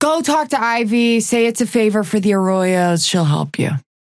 Vyper voice line - Go talk to Ivy, say it's a favor for the Arroyos, she'll help you.